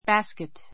bǽskit